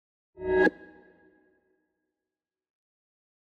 meleeattack-swoosh-magicaleffect-group01-psychic-02.ogg